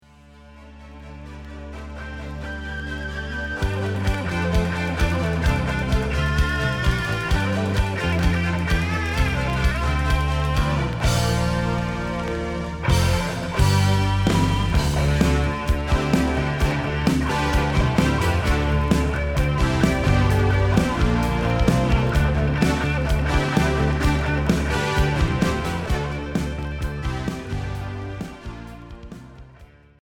Hard FM